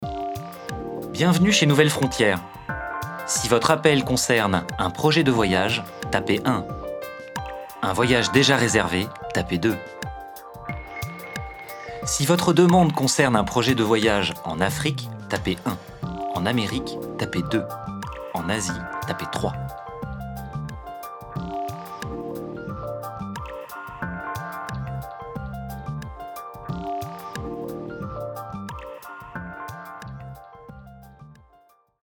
voix pour nouvelle frontière